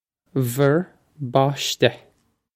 Pronunciation for how to say
vur bawsh-teh
This is an approximate phonetic pronunciation of the phrase.